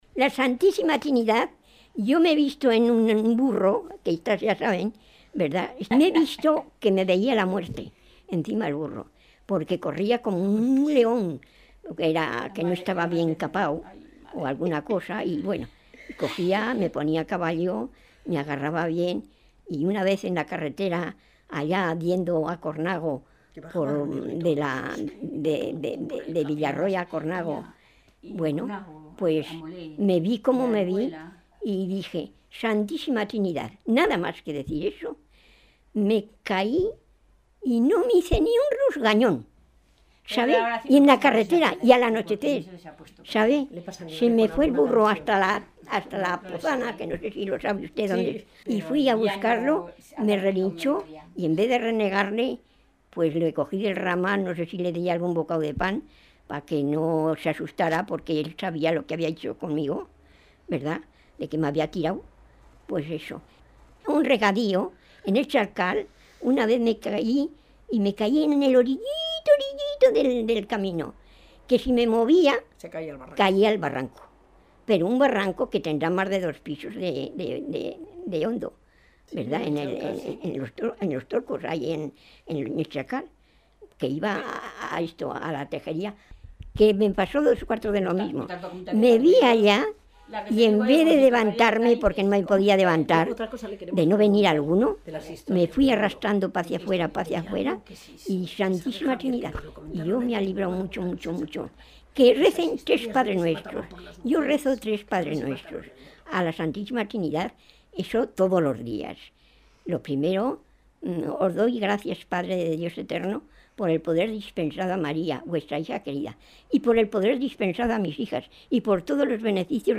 Clasificación: Oraciones
Lugar y fecha de recogida: Logroño, 8 de noviembre de 2000
Prodigios que la informante vivió gracias a su fe en la Santísima Trinidad y las oraciones cultas que rezaba en momentos de necesidad con unas explicaciones llenas de expresividad y detalles dialectales.